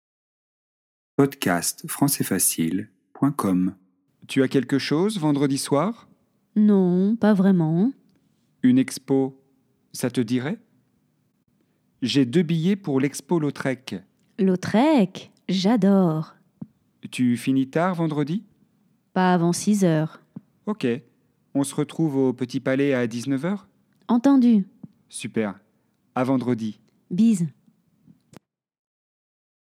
Proposer une sortie - dialogue FLE - Niveau delf A1 - apprendre le français